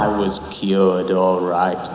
Una raccolta di clip audio dal film